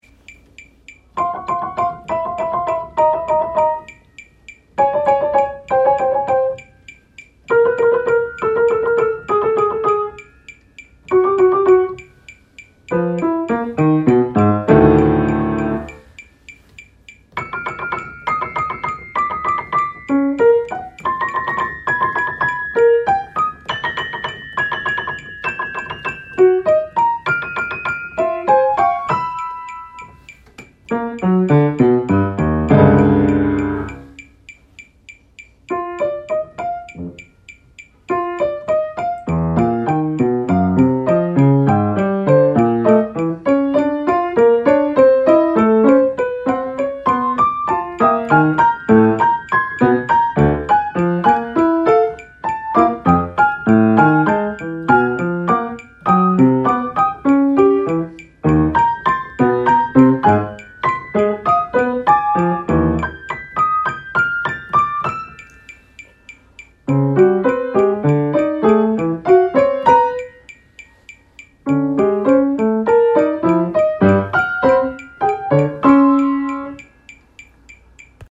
I never touch the damper pedal during this part of the process.
An hour later, after 110, 120, 130, 140, 150, 160, 170, 180, and 190, I’m at 200: